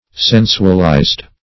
Sensualize \Sen"su*al*ize\, v. t. [imp. & p. p. Sensualized;